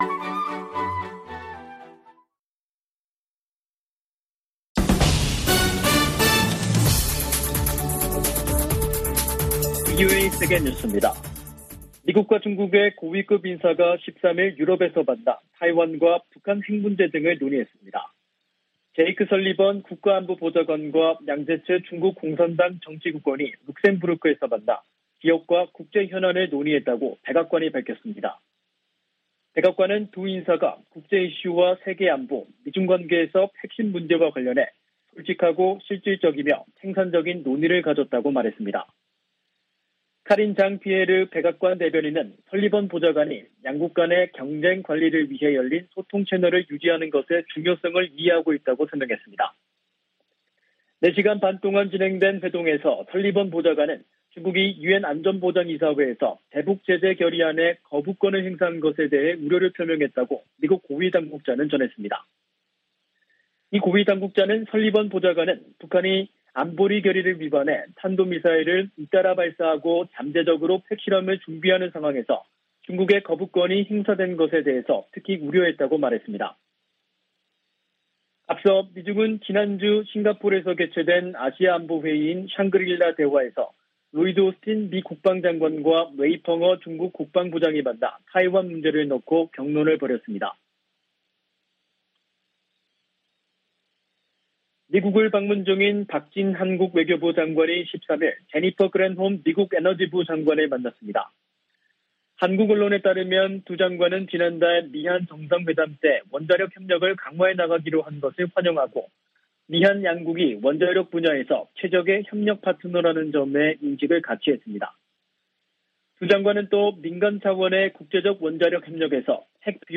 VOA 한국어 간판 뉴스 프로그램 '뉴스 투데이', 2022년 6월 14일 2부 방송입니다. 토니 블링컨 미 국무장관은 북한 핵실험에 단호한 대응을 예고하면서, 대화 응하지 않으면 압박을 강화할 것이라고 경고했습니다.